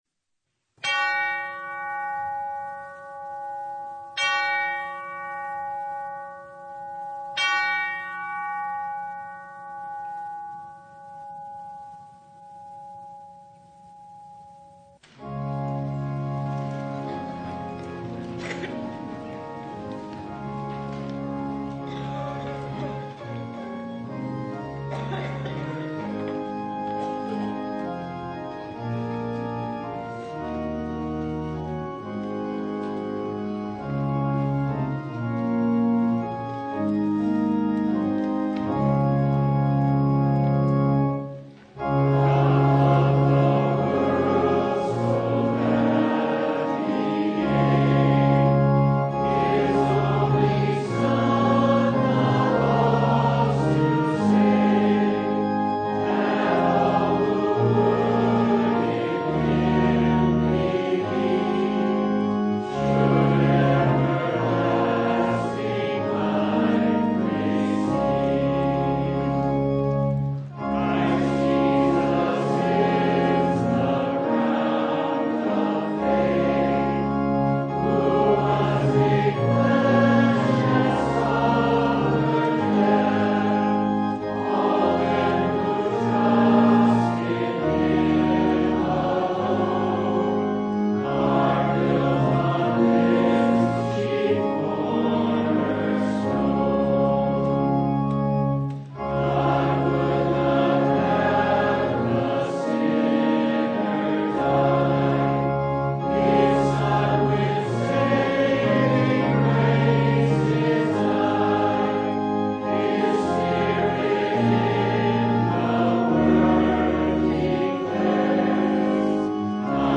Ephesians 5:8–14 Service Type: Sunday Darkness—we’re acquainted with it
Full Service